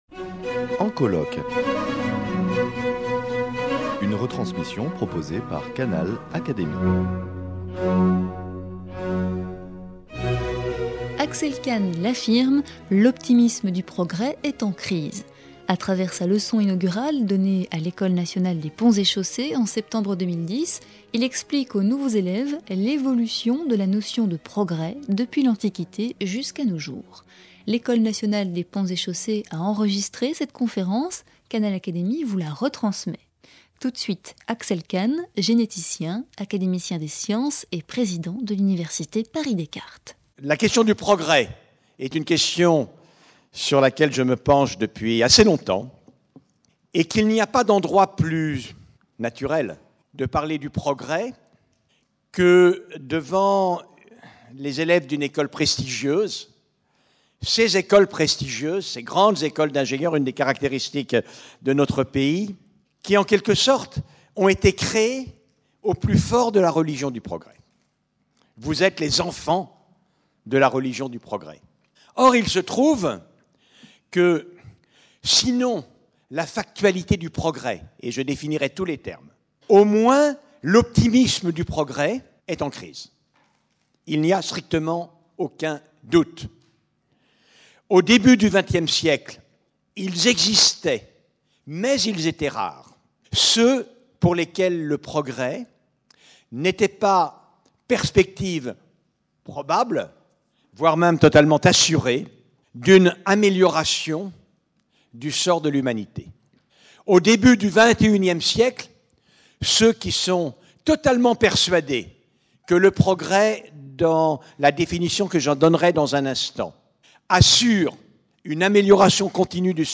A travers sa leçon inaugurale donnée à l’Ecole nationale des Ponts ParisTech, il explique aux étudiants l’évolution de la notion de progrès, depuis l’Antiquité jusqu’à nos jours. Canal Académie retransmet cette intervention qui s’est déroulée en septembre 2010.